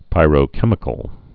(pīrō-kĕmĭ-kəl)